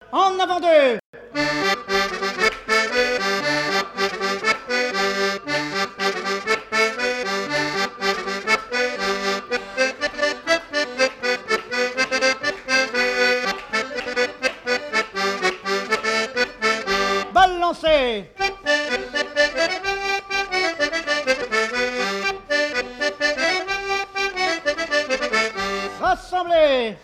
En avant-deux, selon un trio d'accordéons diatoniques
danse : branle : avant-deux
Fête de l'accordéon
Pièce musicale inédite